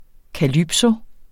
Udtale [ kaˈlybso ]